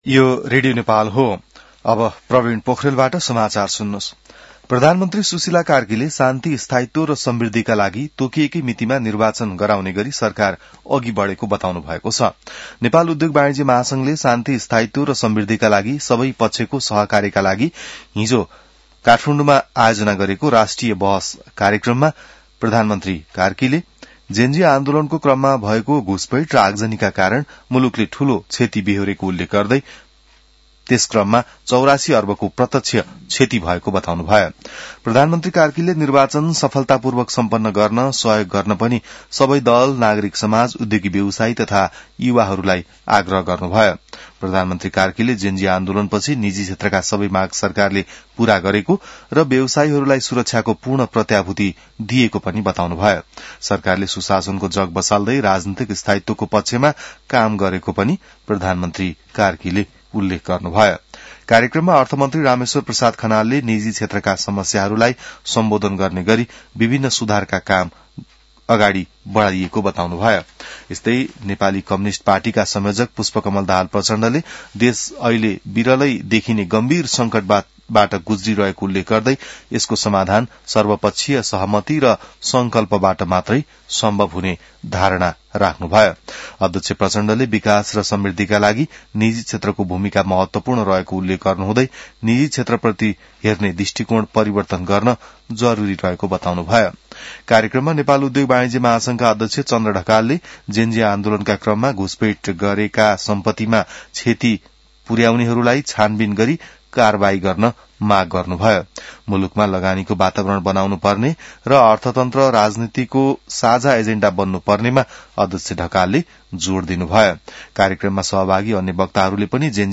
बिहान ६ बजेको नेपाली समाचार : १७ पुष , २०८२